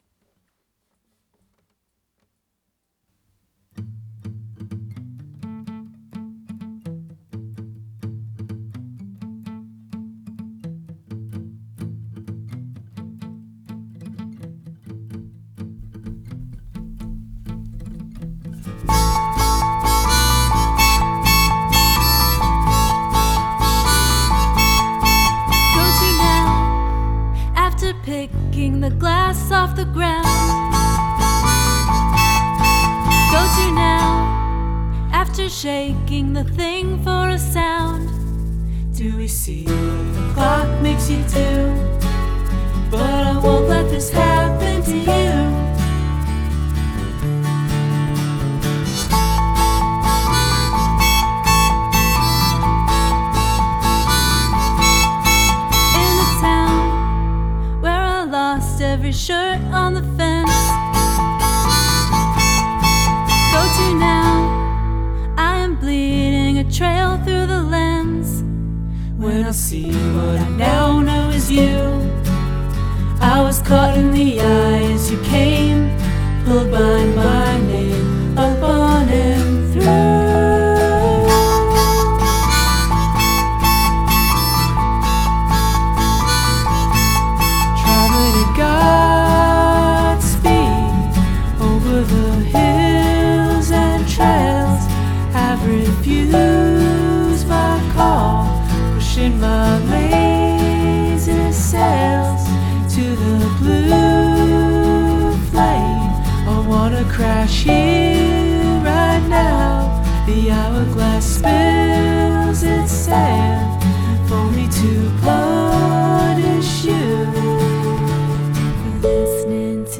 Cover
covers EP